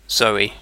Ääntäminen
IPA : /ˈzəʊ.i/